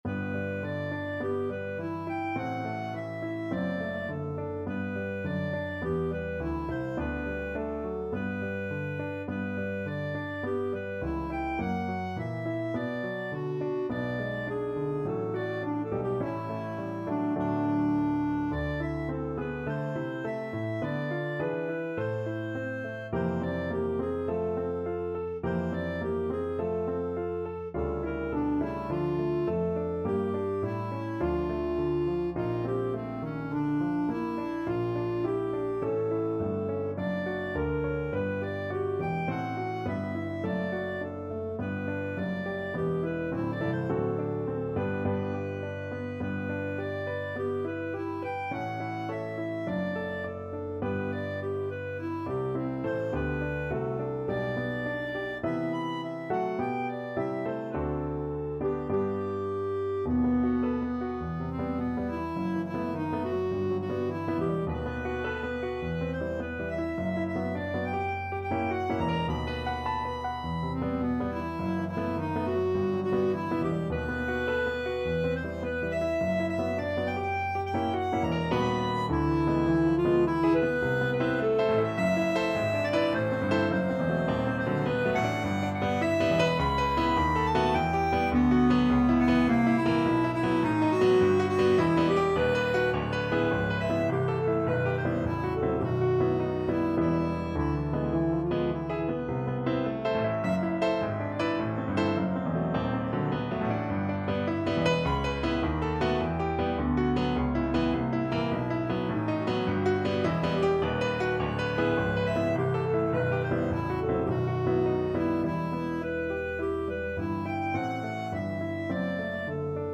Clarinet
G major (Sounding Pitch) A major (Clarinet in Bb) (View more G major Music for Clarinet )
4/4 (View more 4/4 Music)
Einfach, innig =104
Classical (View more Classical Clarinet Music)